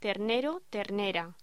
Locución: Ternero, ternera
voz